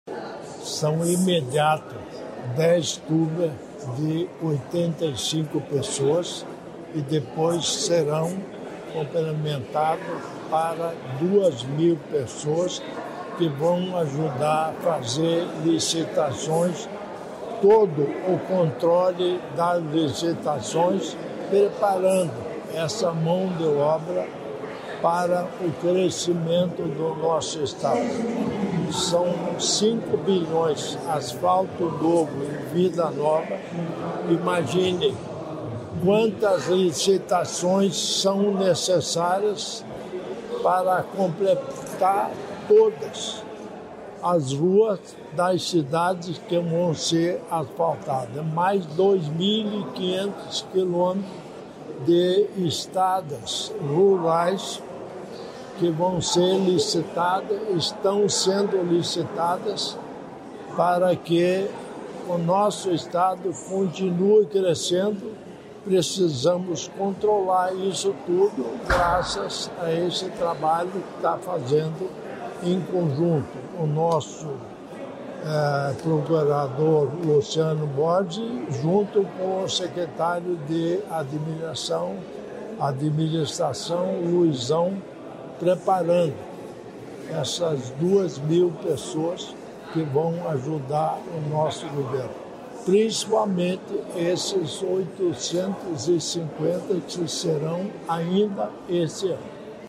Sonora do governador em exercício, Darci Piana, sobre a capacitação de servidores